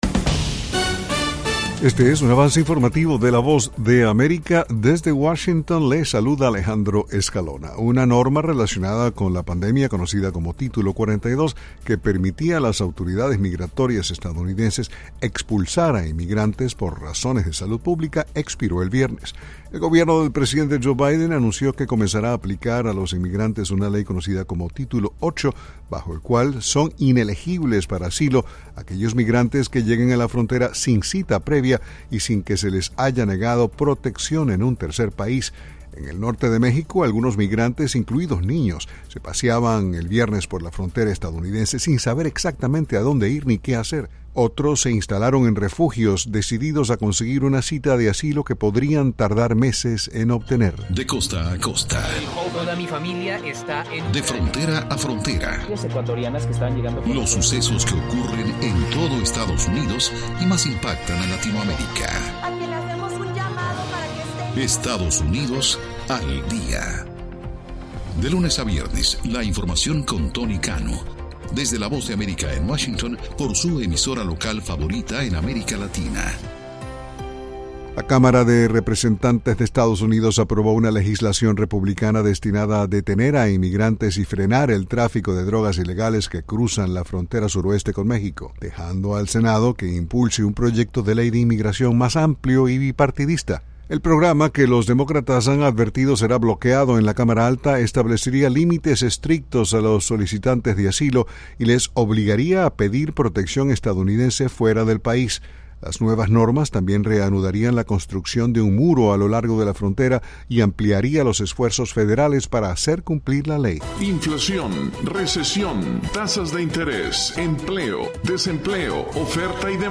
Avance Informativo
Este es un avance informativo presentado por la Voz de América en Washington.